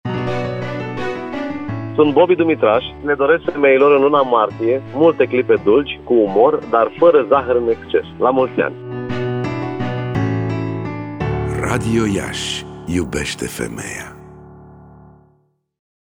De astăzi, timp de o săptămână, pe frecvenţele de 1053 Khz, 90,8, 94,5 şi 96,3 Mghz, vor fi difuzate următoarele spoturi: